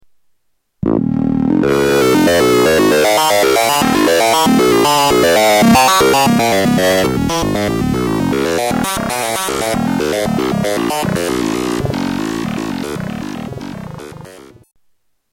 Category: Sound FX   Right: Personal
Tags: Sound Effects EML ElectroComp 101 EML101 ElectroComp 101 Synth Sounds